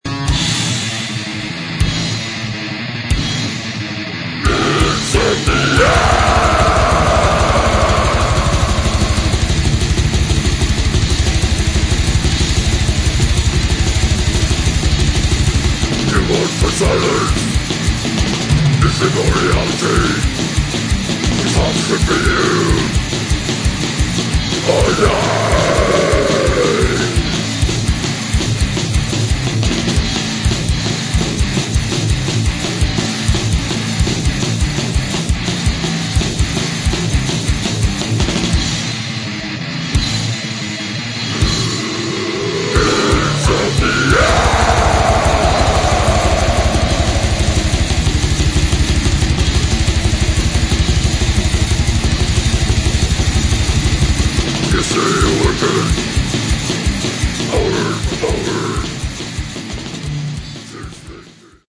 Брутальный Death Metal.
Некоторое влияние Crust и Grind.